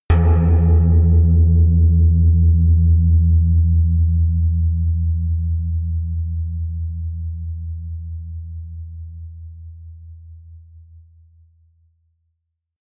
Royalty free music elements: Tones